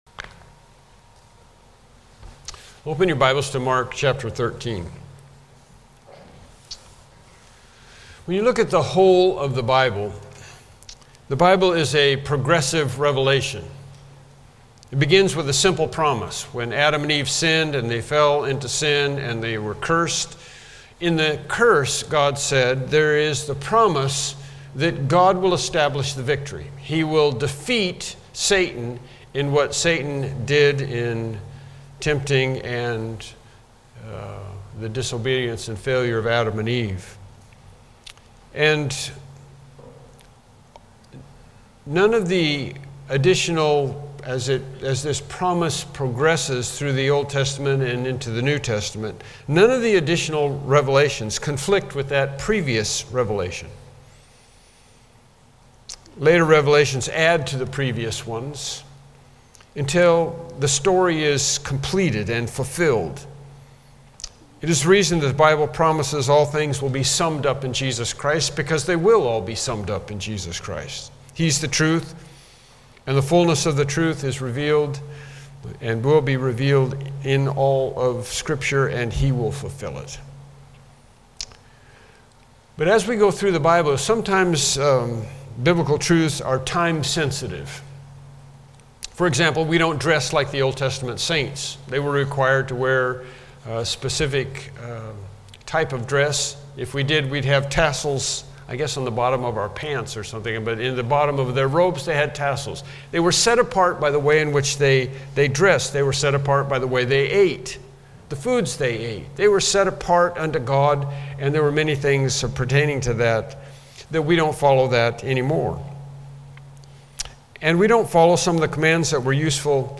Mark 13:9-13 Service Type: Morning Worship Service « Lesson 14